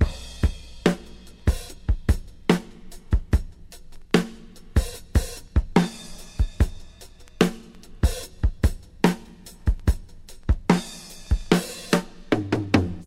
• 73 Bpm HQ Breakbeat Sample D Key.wav
Free drum beat - kick tuned to the D note. Loudest frequency: 749Hz
73-bpm-hq-breakbeat-sample-d-key-Xyj.wav